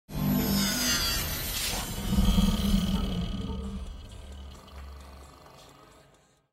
Звук входа в Матрицу сквозь экран монитора